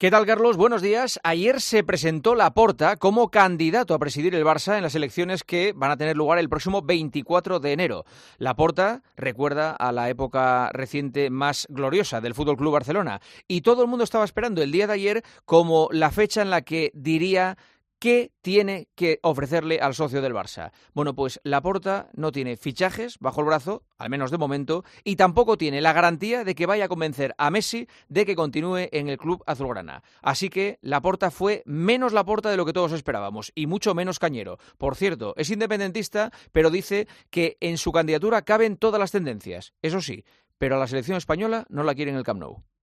Juanma Castaño analiza la actualidad deportiva en 'Herrera en COPE'